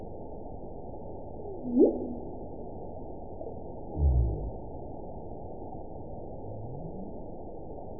event 921813 date 12/19/24 time 07:04:39 GMT (10 months ago) score 9.62 location TSS-AB02 detected by nrw target species NRW annotations +NRW Spectrogram: Frequency (kHz) vs. Time (s) audio not available .wav